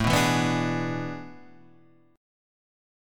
AM7sus2sus4 chord